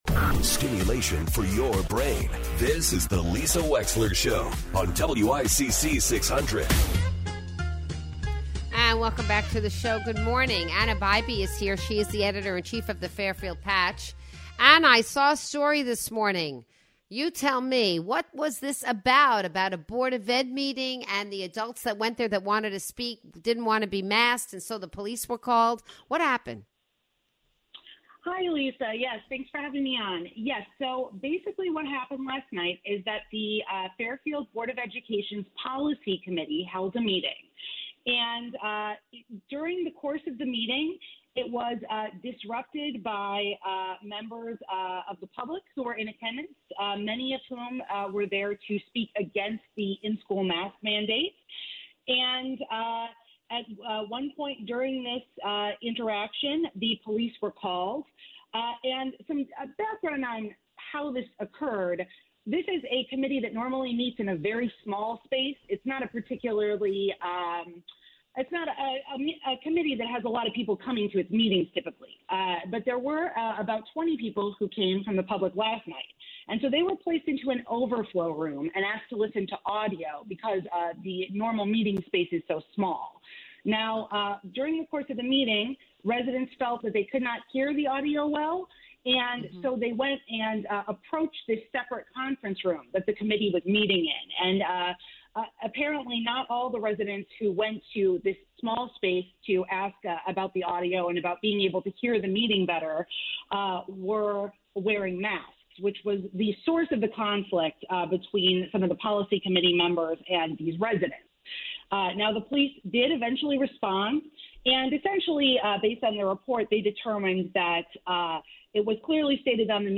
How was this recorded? calls in to report on an anti-mask protest at a Board of Education meeting.